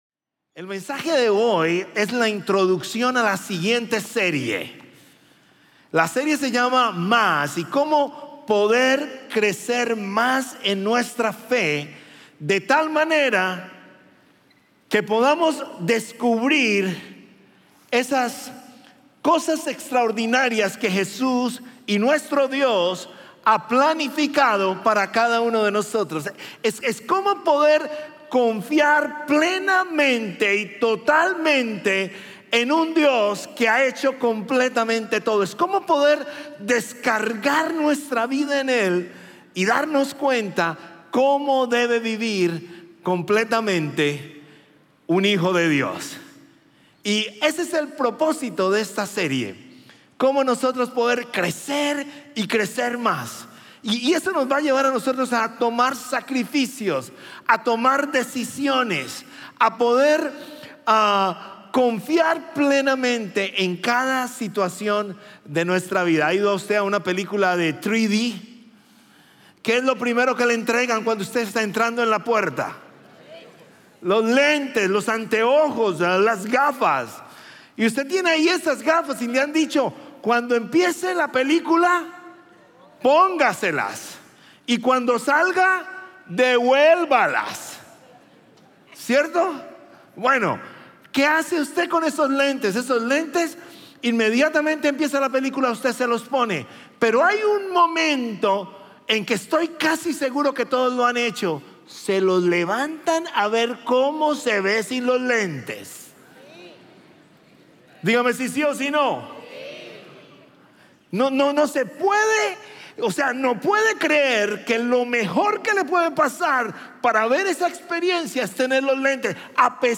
Un mensaje de la serie "Evidencias - JV ."